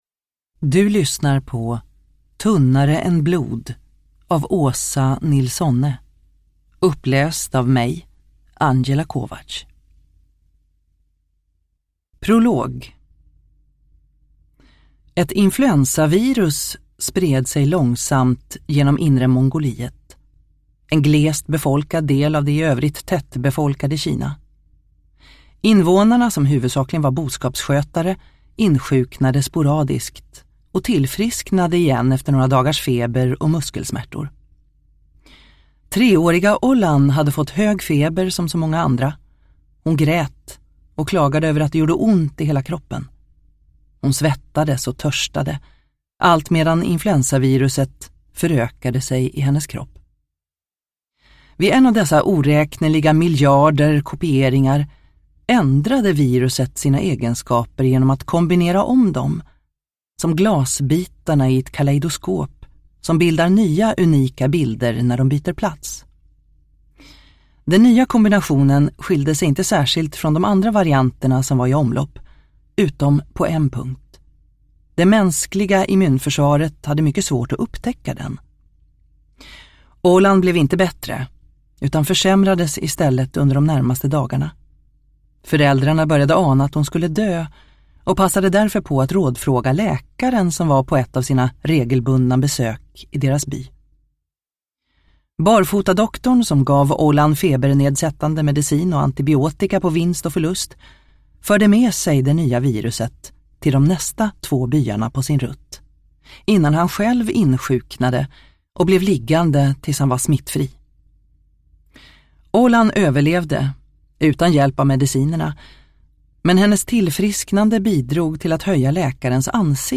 Tunnare än blod (ljudbok) av Åsa Nilsonne